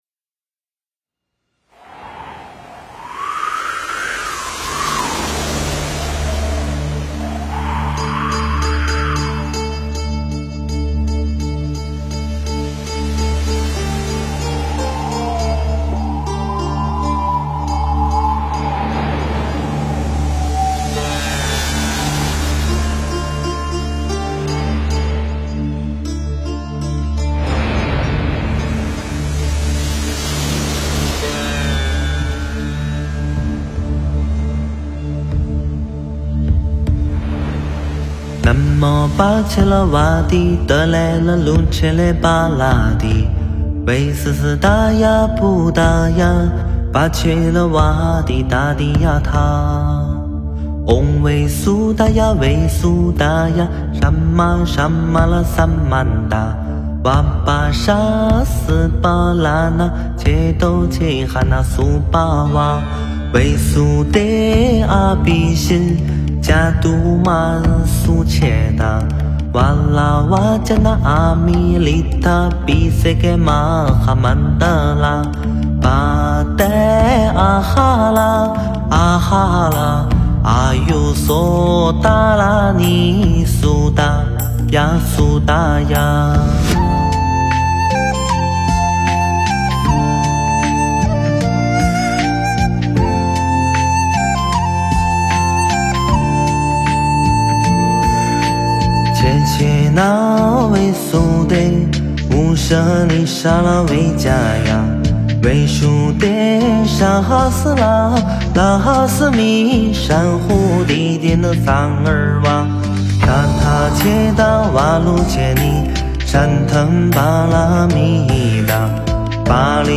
诵经
佛音 诵经 佛教音乐 返回列表 上一篇： 安逸忏心 下一篇： 观音灵感真言(梦授咒